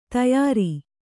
♪ tayāri